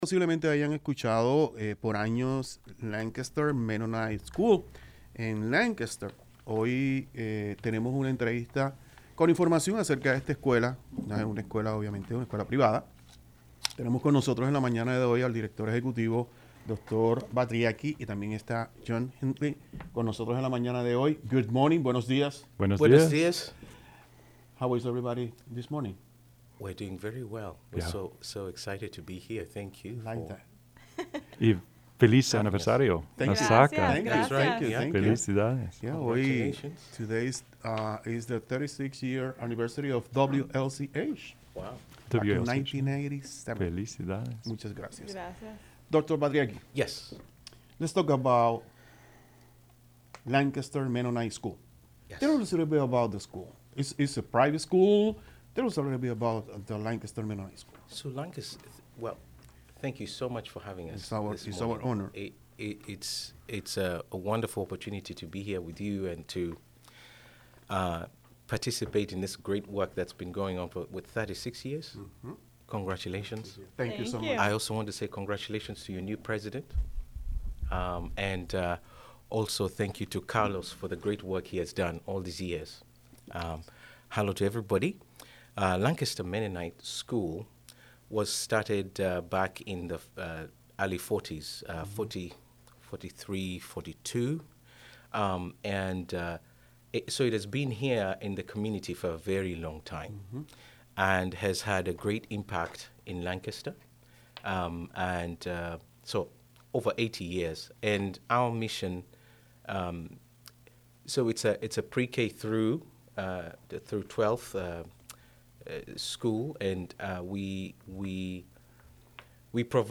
Radio Centro Interview with LM Administrators